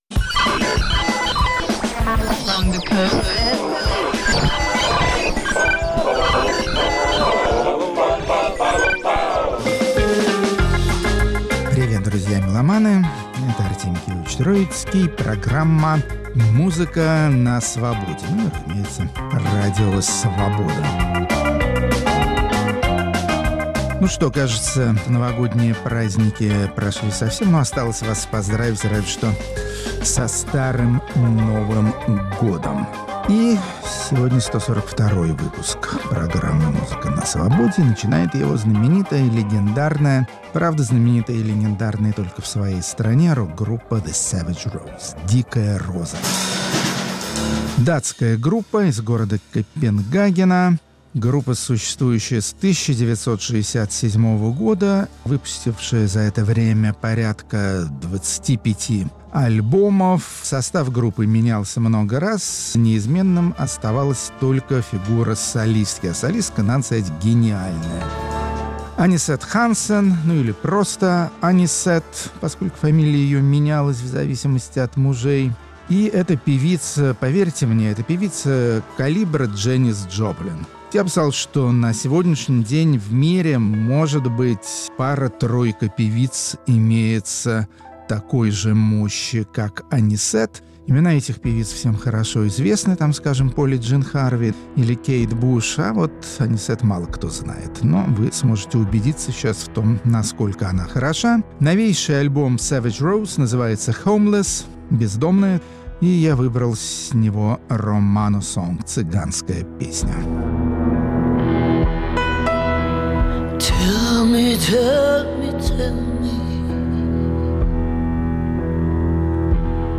Музыка на Свободе. 19 января, 2020 Музыканты маститого немецкого рок-коллектива Einstürzende Neubauten. Рок-критик Артемий Троицкий внимательно следит сам и разъясняет другим, как из разных авангардистских шумов рождается стройная музыка.